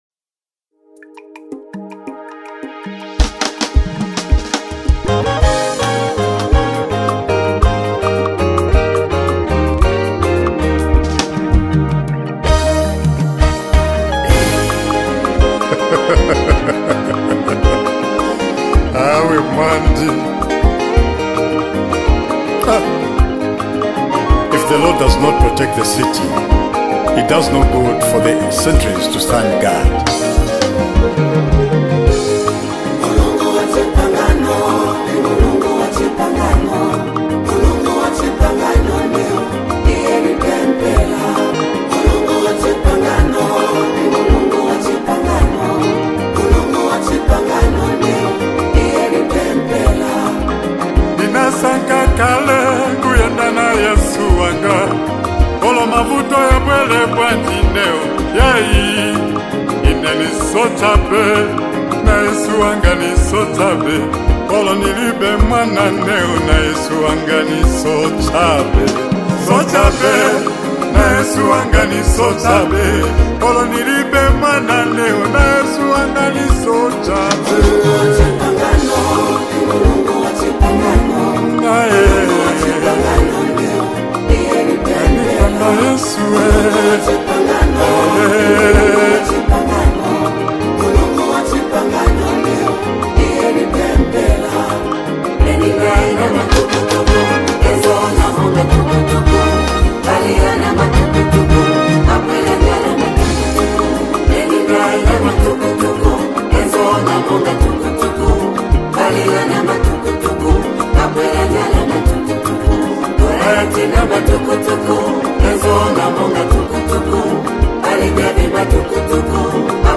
the beautiful blend of Rumba and gospel!